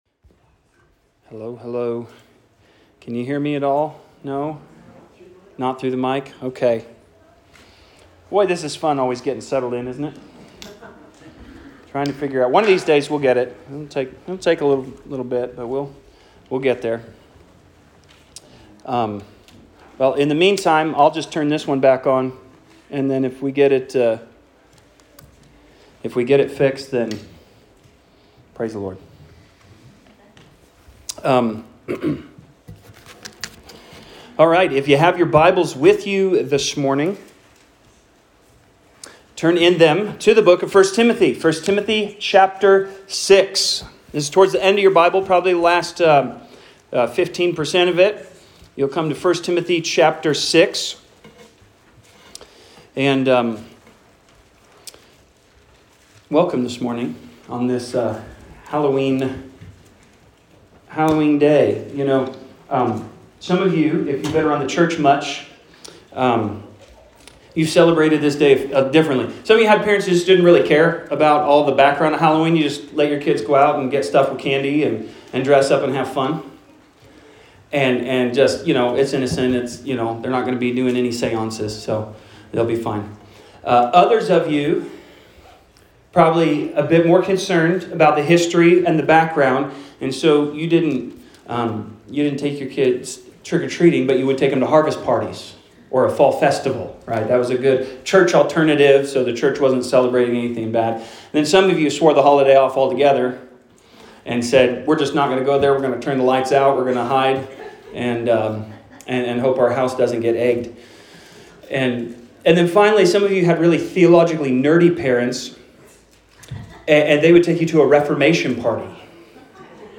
Sermons | Grace Gospel Church